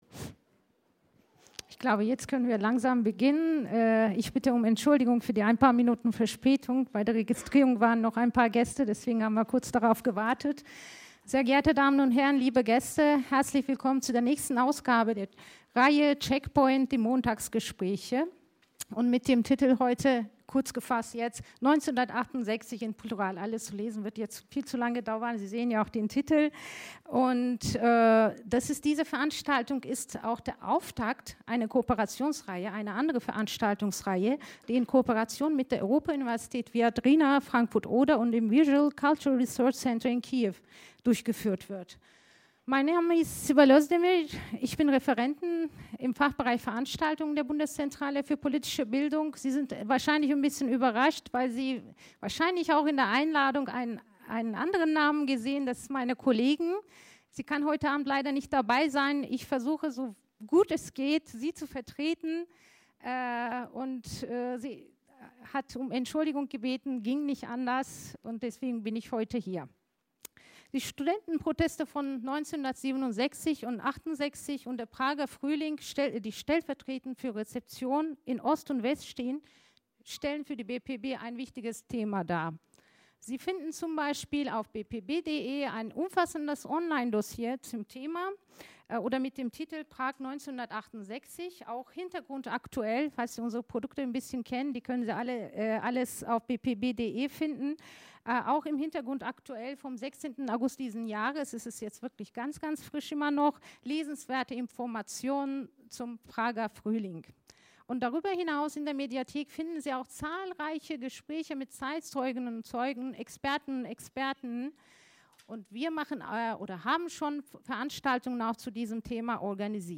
► Follow this link to listen to the audio recording of the panel discussion.